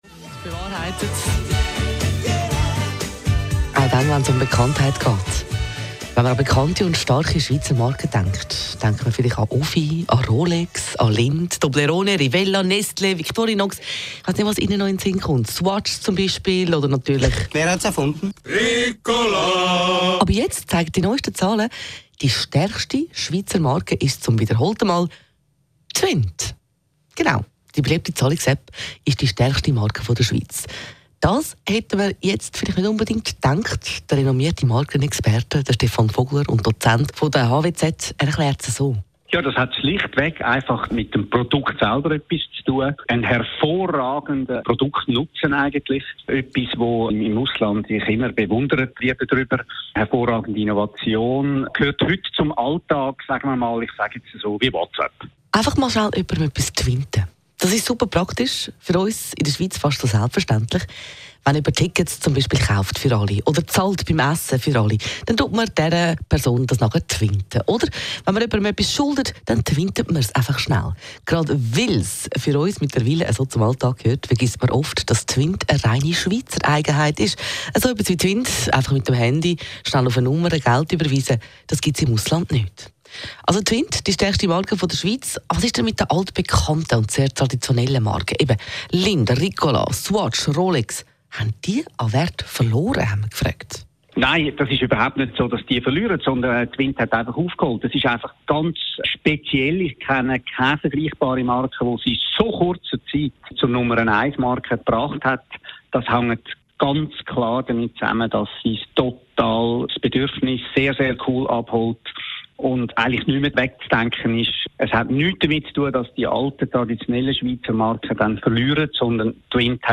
Radio-Interview (Audio) Datenschutzerklärung